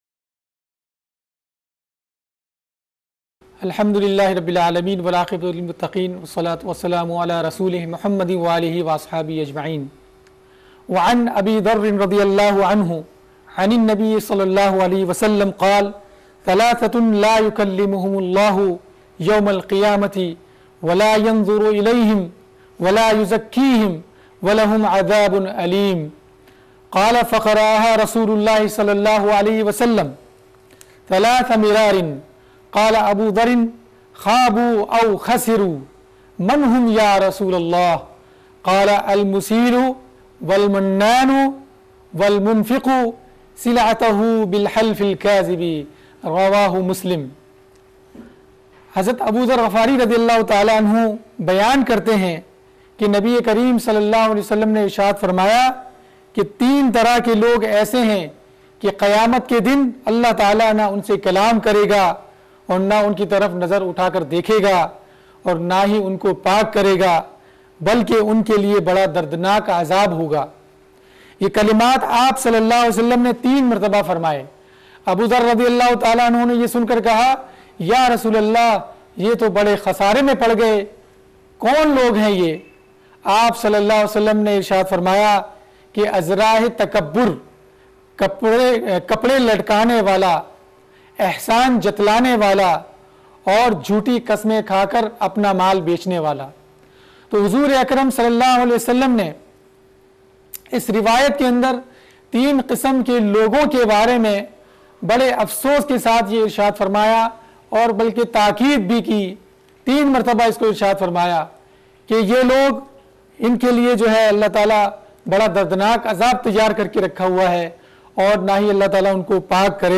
OTHERS LECTURES